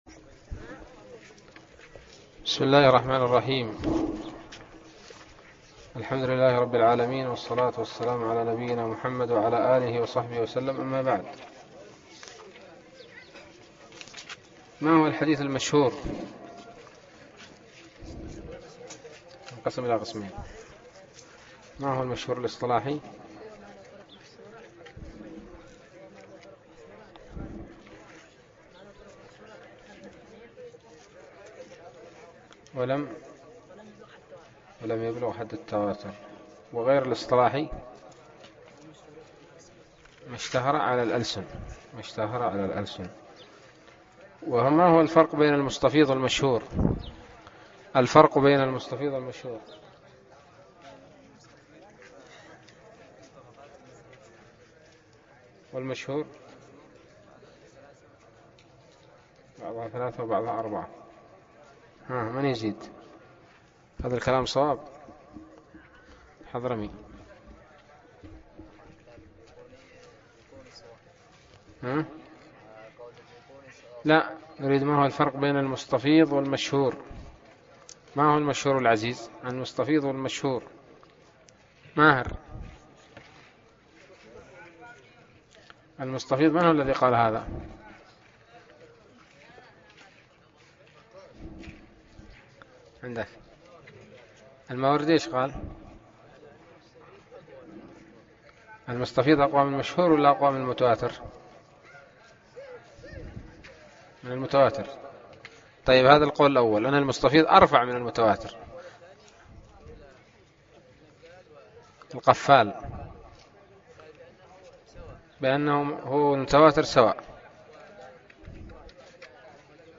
الدرس التاسع والأربعون من الباعث الحثيث